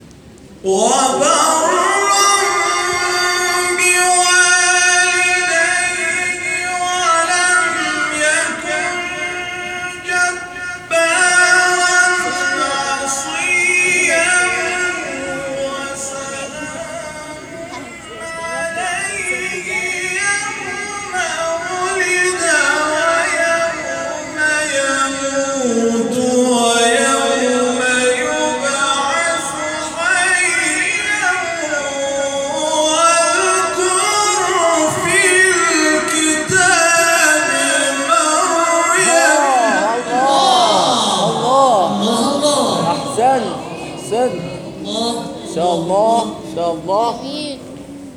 گروه فعالیت‌های قرآنی: فرازهایی شنیدنی از قاریان ممتاز کشور را می‌شنوید.